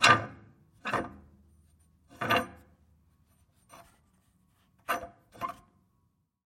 Большой запорный вентиль со звуком